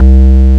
BASS48  01-R.wav